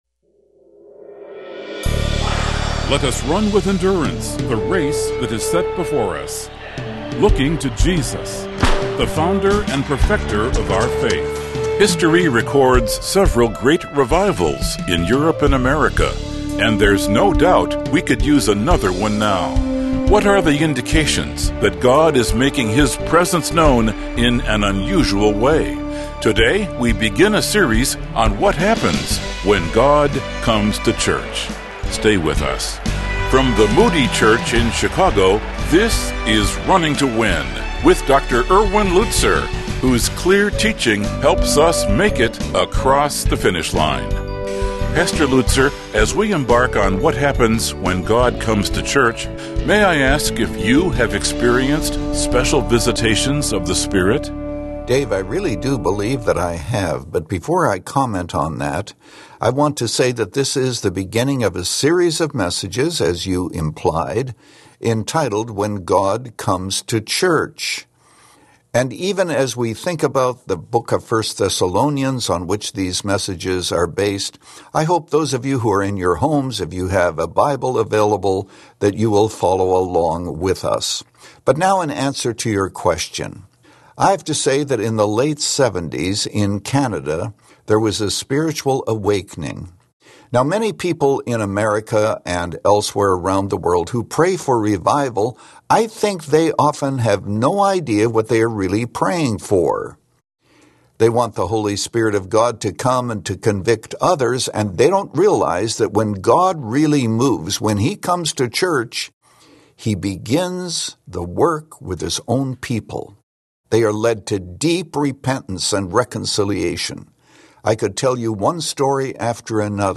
In this message from 1 Thessalonians 1, Pastor Lutzer gives the first of five characteristics of a new, living church.